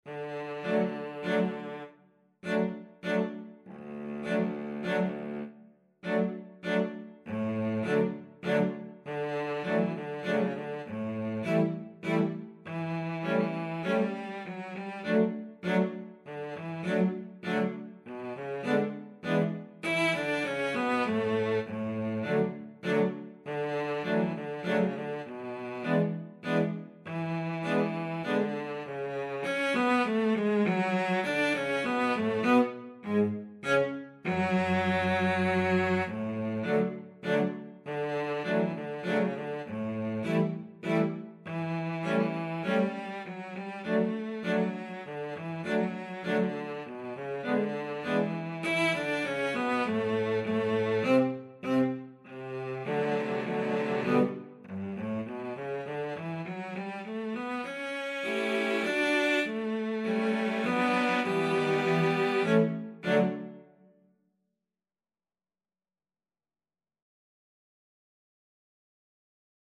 3/4 (View more 3/4 Music)
Slowly = c.100
Cello Quartet  (View more Easy Cello Quartet Music)